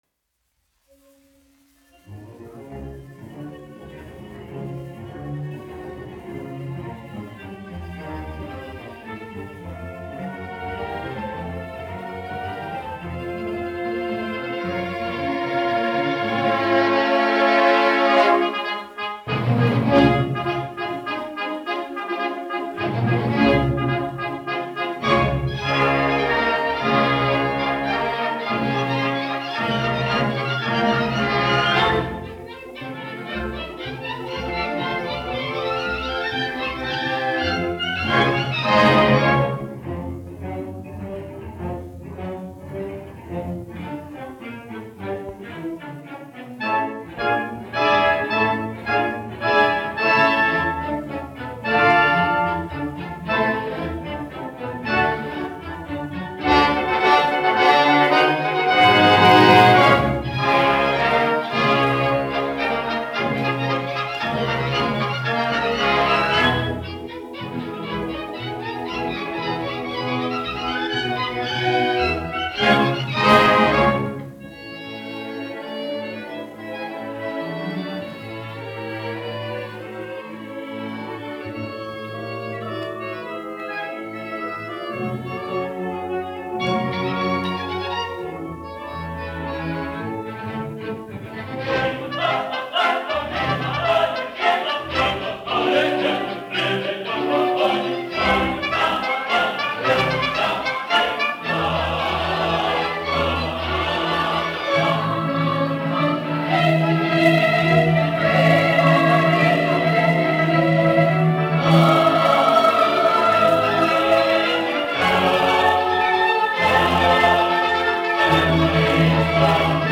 Latvijas Nacionālā opera. Koris, izpildītājs
Emil Cooper, diriģents
1 skpl. : analogs, 78 apgr/min, mono ; 30 cm.
Operas--Fragmenti
Latvijas vēsturiskie šellaka skaņuplašu ieraksti (Kolekcija)